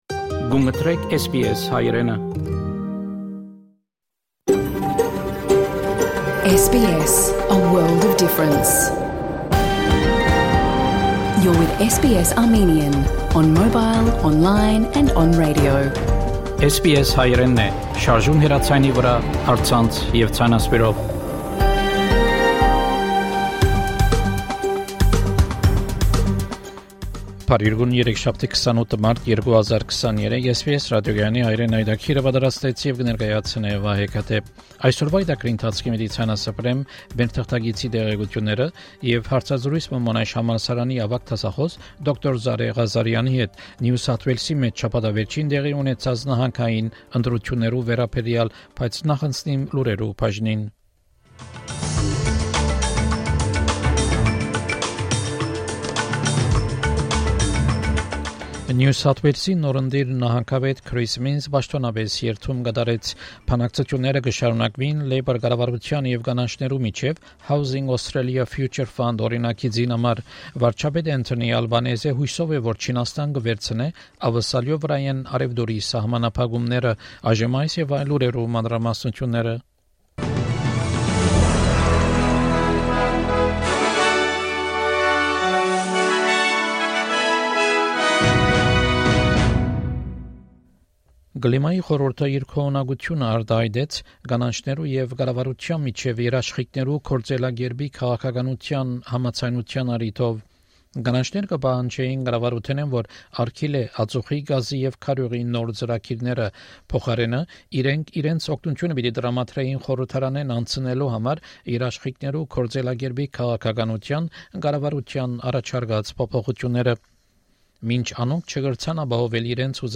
SBS Armenian news bulletin – 28 March 2023